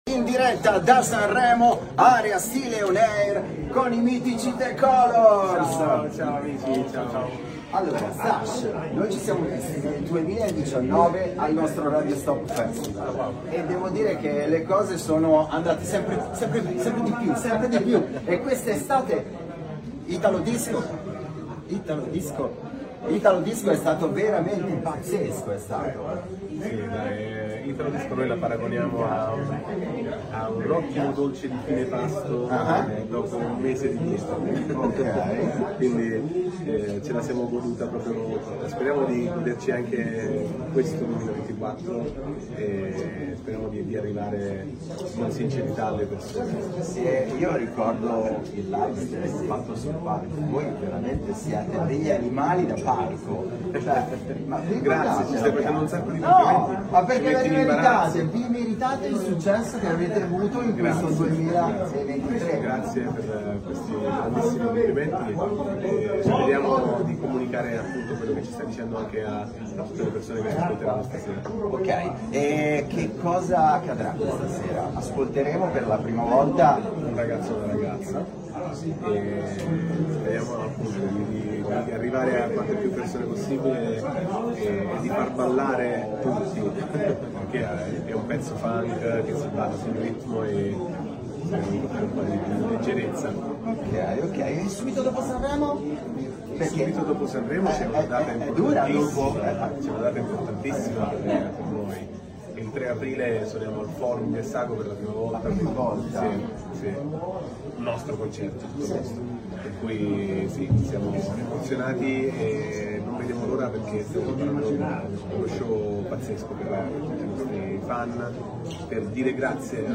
Festival di Sanremo con Radio Stop!
Radio Stop – Intervista a THE COLOR
Intervista-a-THE-COLOR.mp3